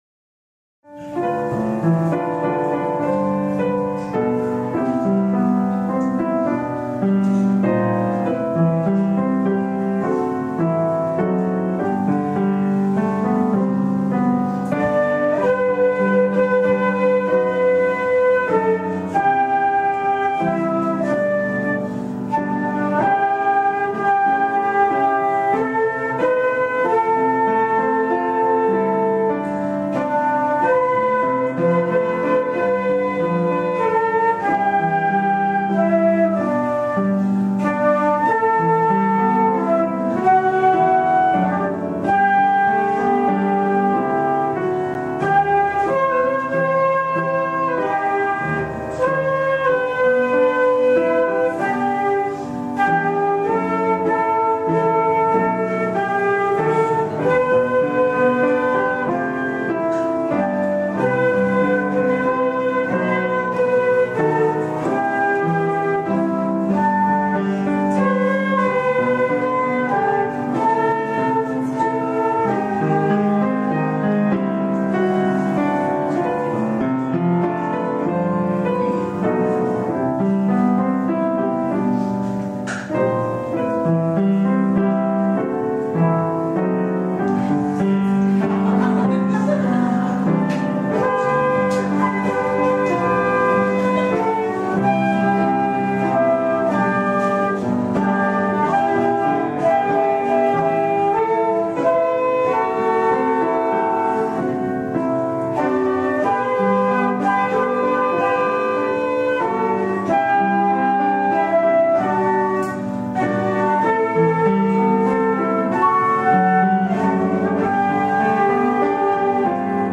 풀룻 연주